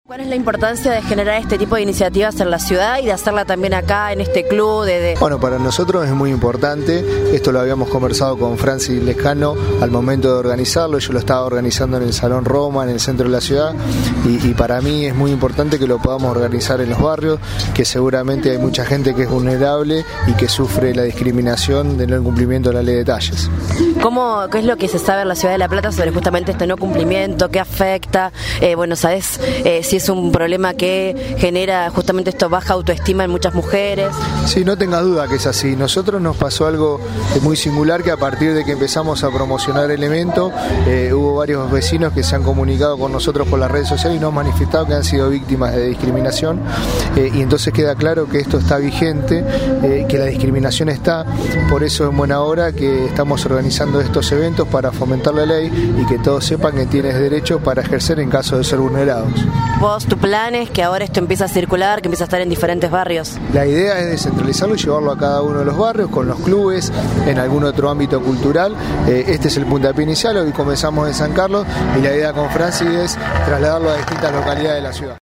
El concejal Gerardo Jazmín del bloque Alternativa Vecinal Platense, acompañó la iniciativa y manifestó que «es muy importante que se pueda hacer en los barrios donde hay mucha gente vulnerable y que sufre la discriminación del no cumplimiento de la Ley de Talles».